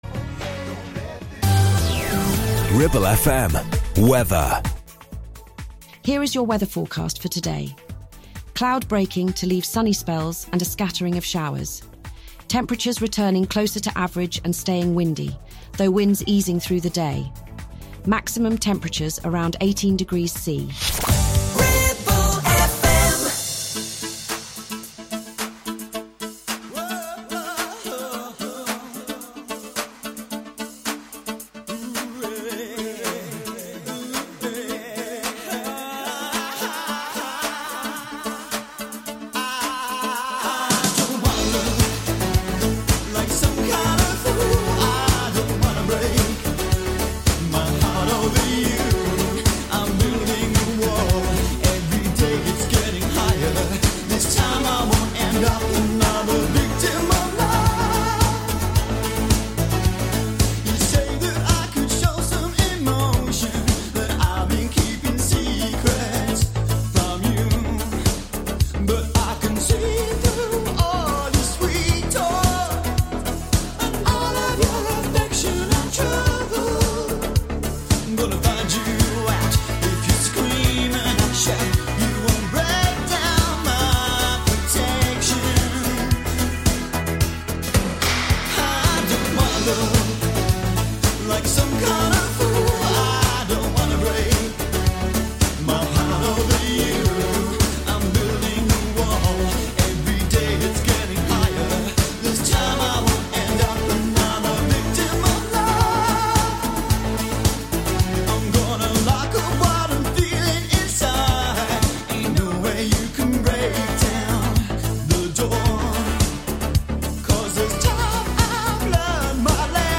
magazine show which encompasses life in and around the Ribble Valley, top topics and guests.